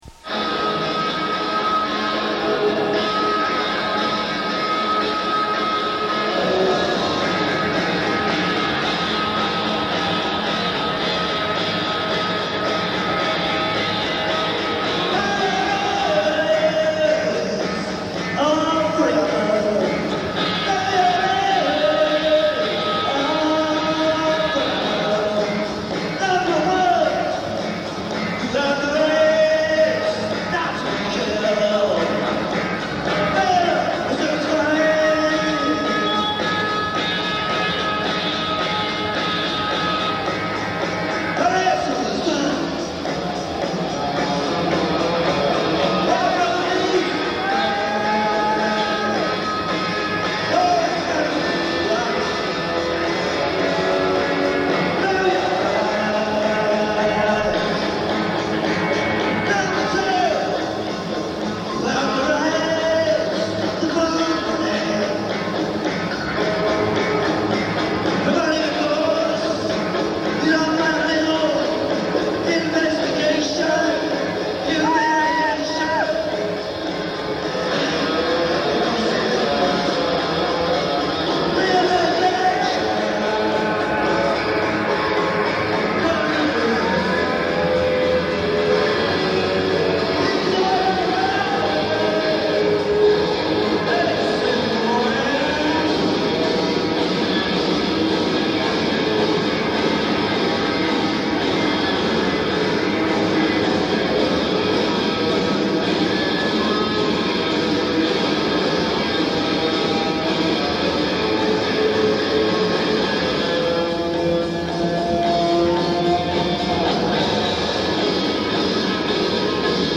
Penn Rink, Philadelphia 8-27-82